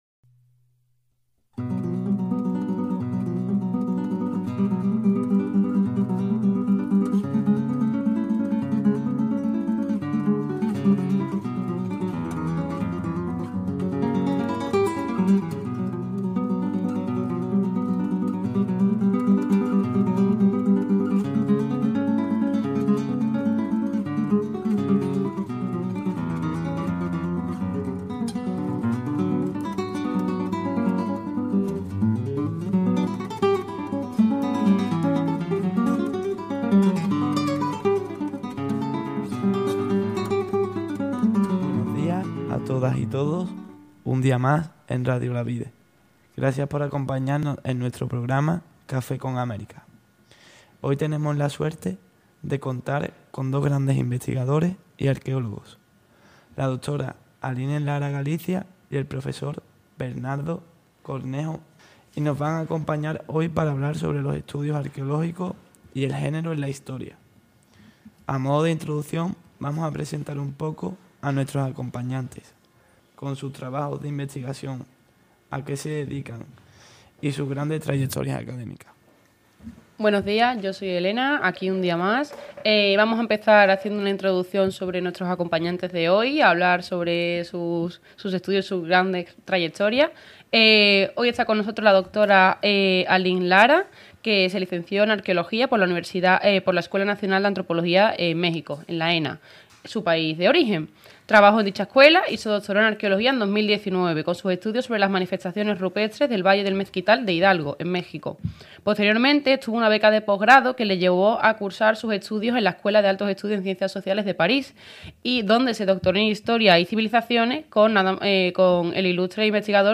Noviembre - Café con América 'Entrevista a la Dra.